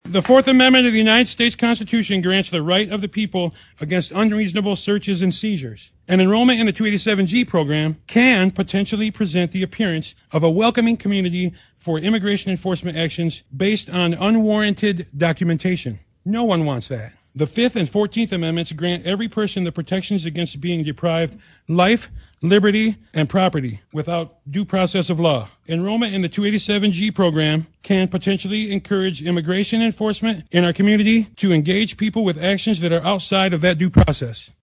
Jackson, Mich. (WKHM) — The Jackson County Board of Commissioners meeting on Tuesday night featured a packed room and multiple hours of public comment regarding the Sheriff’s Office’s (JCSO) 287(g) Agreement with ICE.